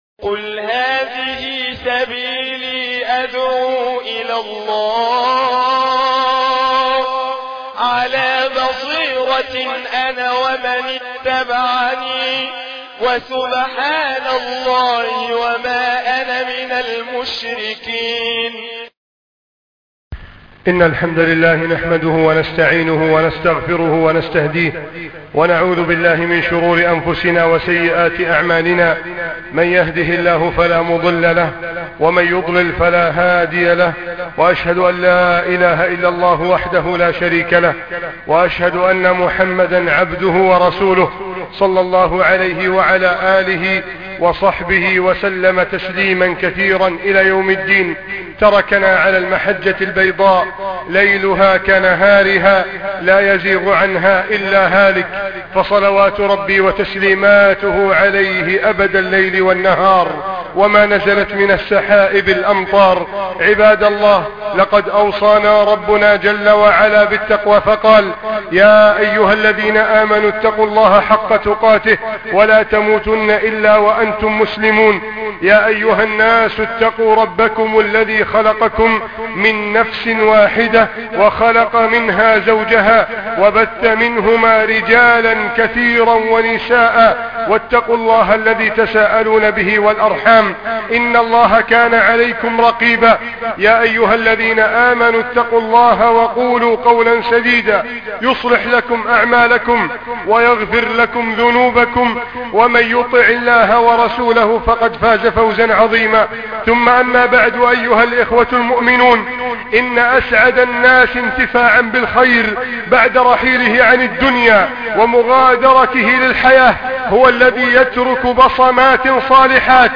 بصمات (خطبة الجمعة